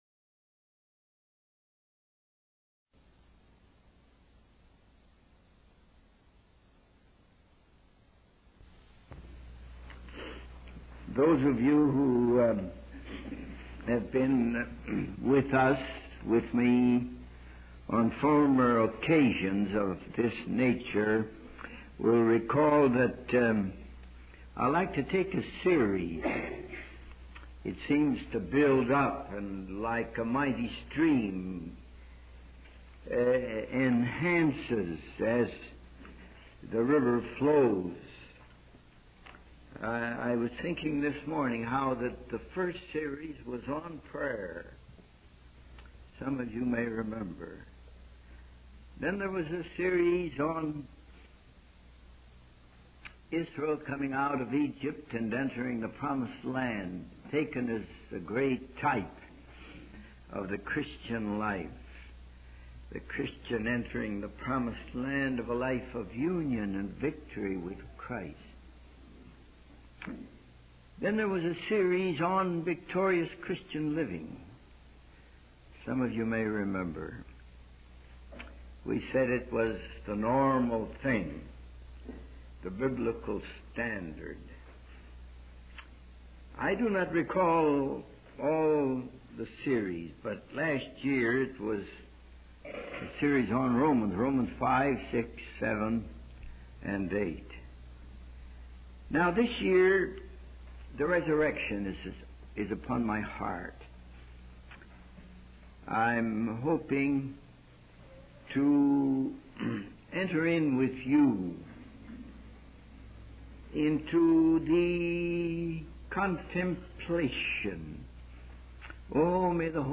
In this sermon, the speaker reflects on previous sermon series and announces his current focus on the resurrection. He expresses his desire for the Holy Spirit to reveal the significance of the resurrection in a new and practical way for the Christian life. The speaker also highlights the issue of some Christians having a 'dead Christ' in their beliefs and practices.